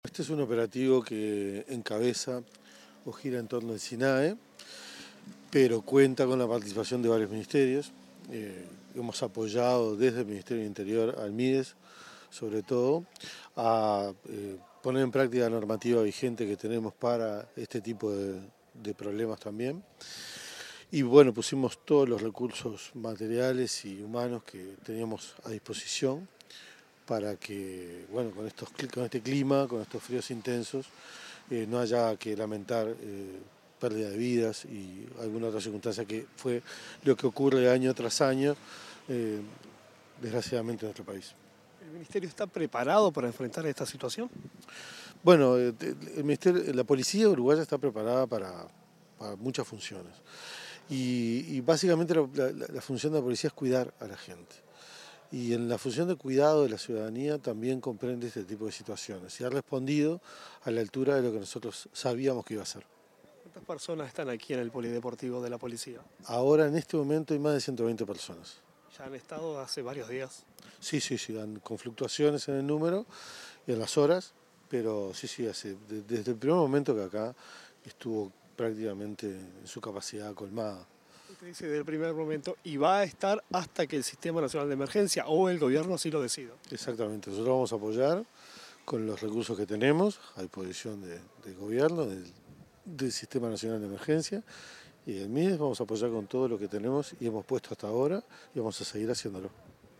Declaraciones del ministro del Interior, Carlos Negro
Declaraciones del ministro del Interior, Carlos Negro 29/06/2025 Compartir Facebook X Copiar enlace WhatsApp LinkedIn Tras una visita al polideportivo de la Escuela Nacional de Policía, donde se dispuso un centro de evacuación para personas en situación de calle por frío extremo, el ministro del Interior, Carlos Negro, brindó declaraciones a la prensa acerca de la participación del organismo que dirige ante la alerta de nivel rojo.